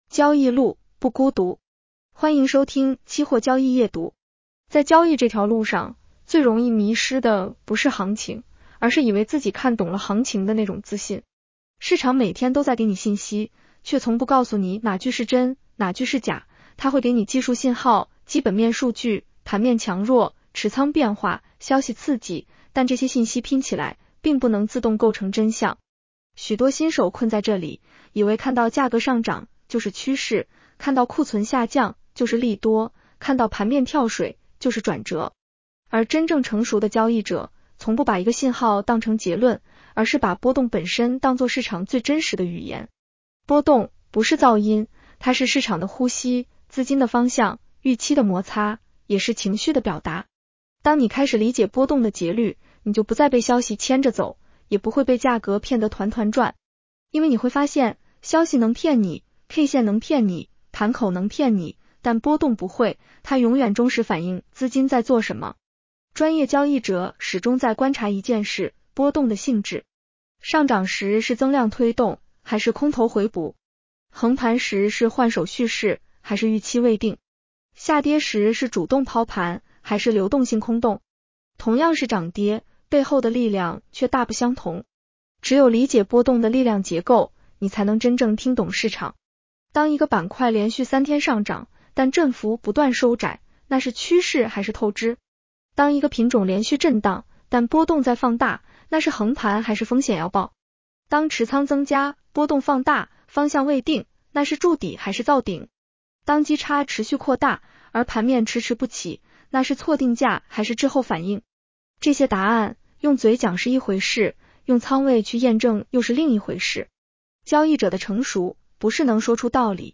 女声普通话版 下载mp3
（AI生成）